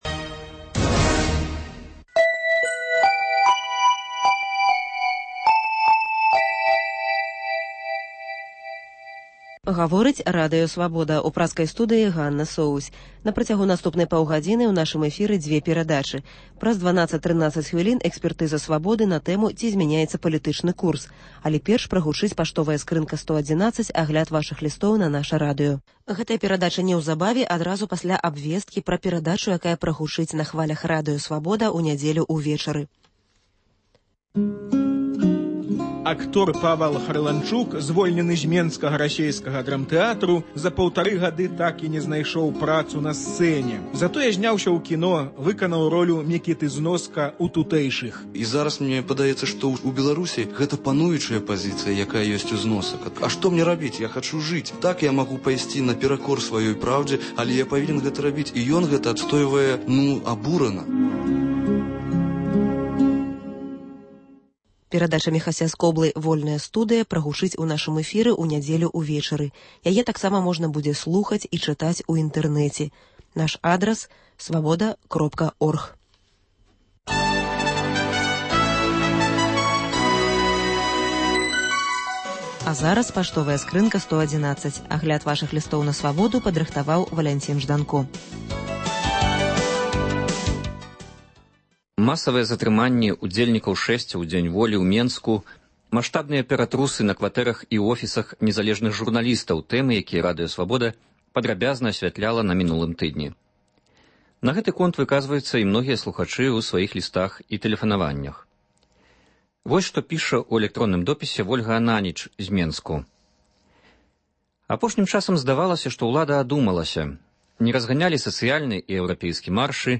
Круглы стол на тэму "Літаратура і БНР"